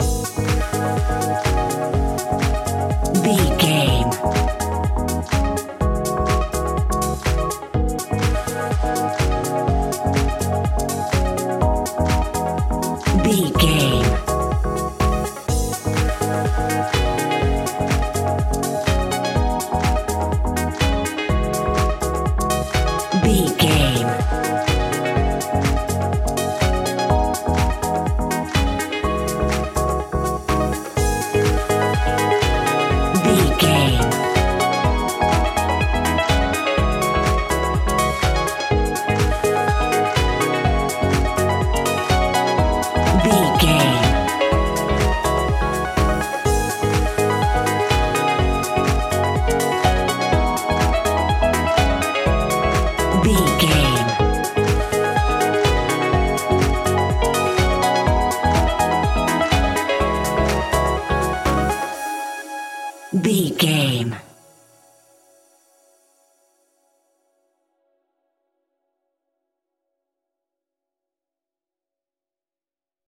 Aeolian/Minor
G#
groovy
uplifting
driving
energetic
bouncy
synthesiser
drum machine
electro house
synth bass